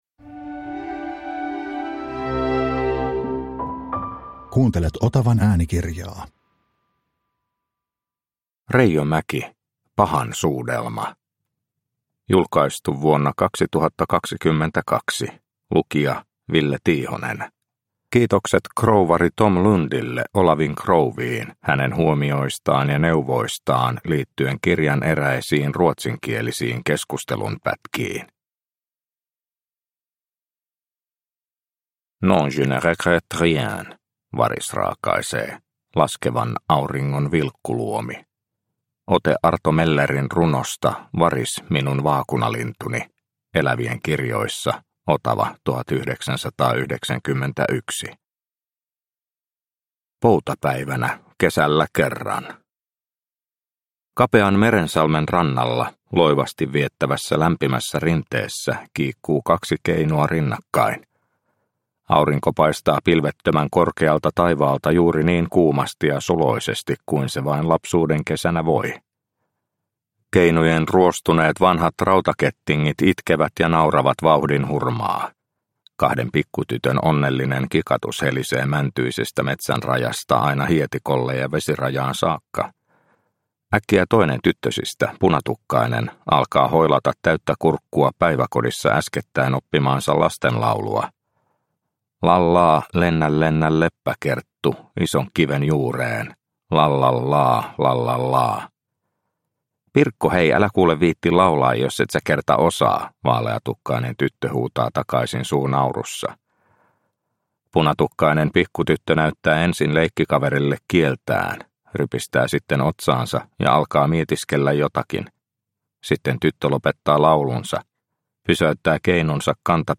Pahan suudelma – Ljudbok – Laddas ner